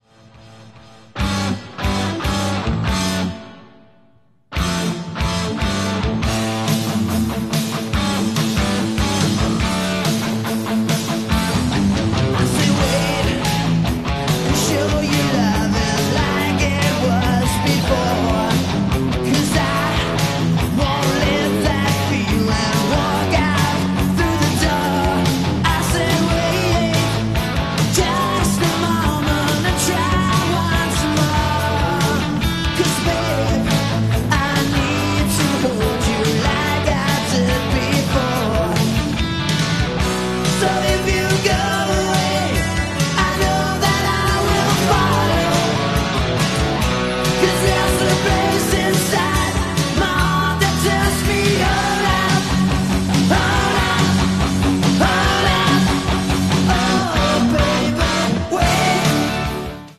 glam-metal band
power ballad
rhythm guitar
lead guitar
drums